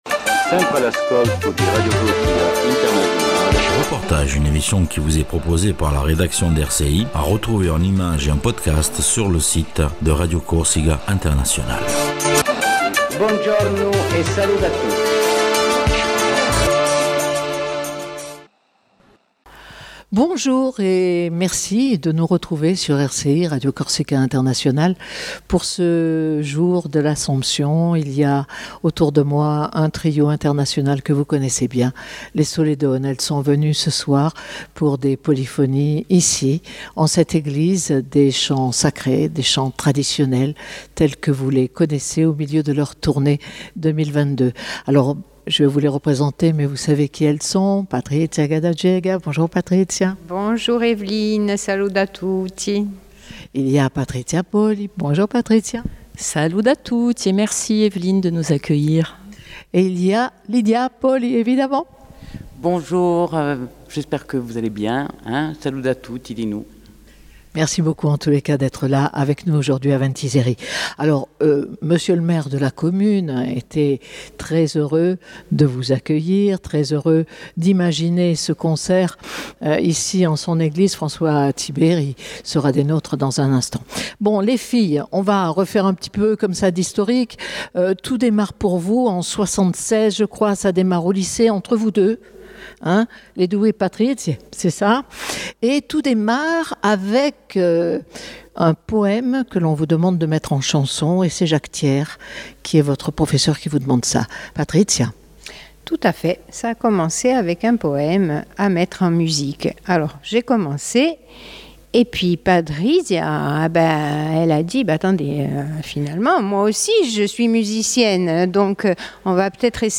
REPORTAGE DU TRIO SOLEDONNA